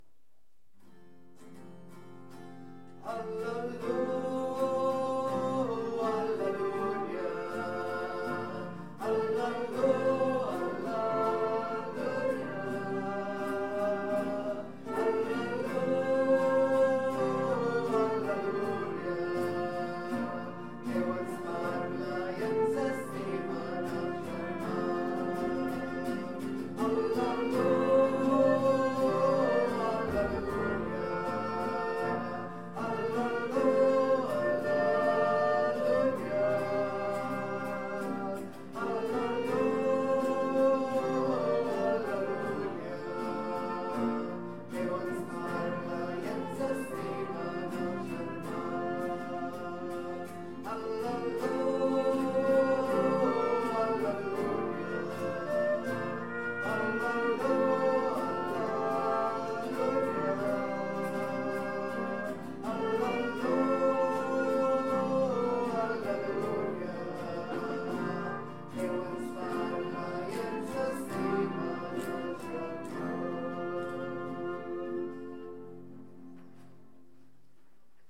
Pregària de Taizé
Capella dels Salesians - Diumenge 26 d'abril de 2015
Vàrem cantar...